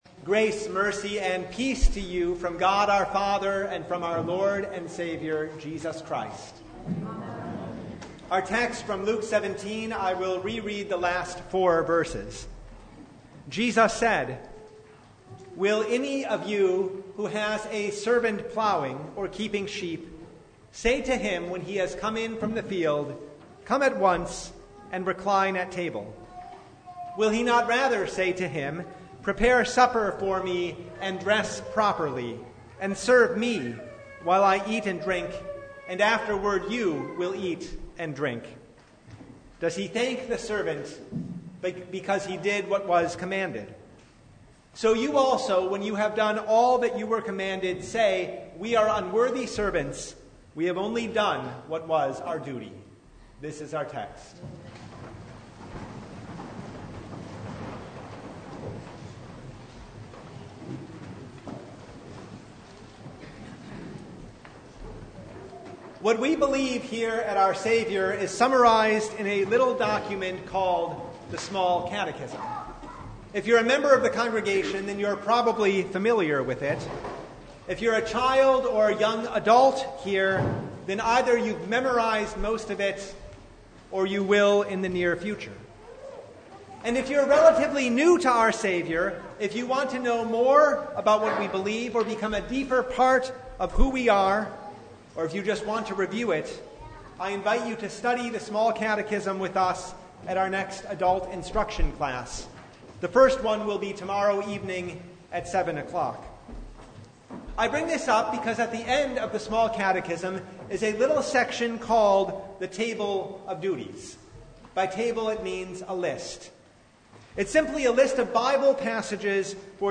Luke 17:1-10 Service Type: Sunday Do we expect to be applauded for simply doing our duty?